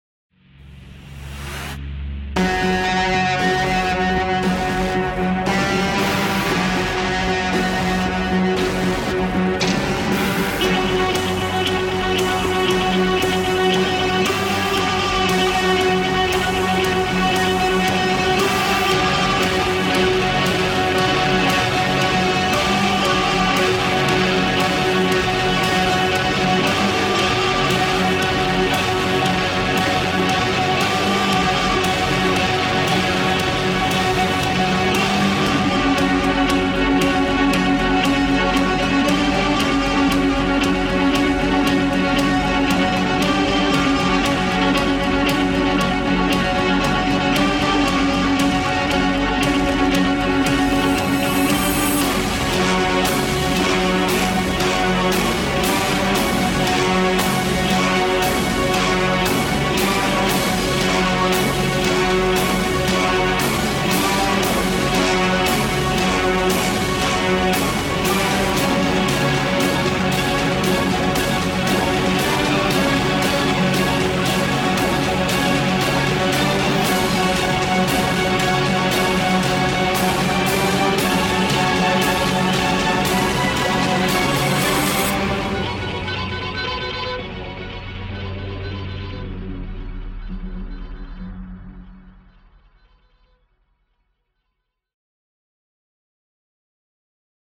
Hybrid track for racing and action.